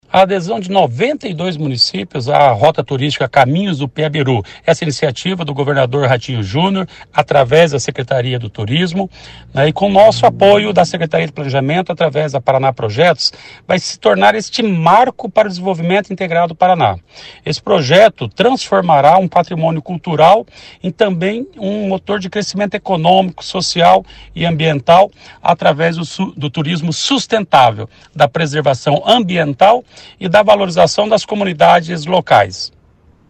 Sonora do secretário Estadual do Planejamento, Ulisses Maia, sobre a nova etapa do programa Rota Turística Caminhos do Peabiru